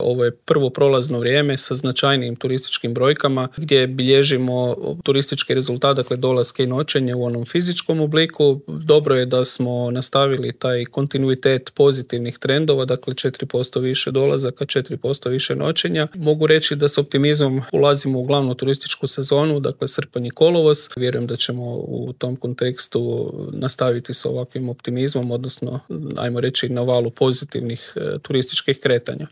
Brojke za prvih pola godine su izrazito dobre, a u Intervjuu tjedna Media servisa prokomentirao ih je direktor Hrvatske turističke zajednice Kristjan Staničić: